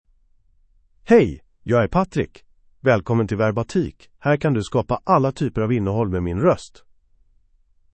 MaleSwedish (Sweden)
Voice sample
Male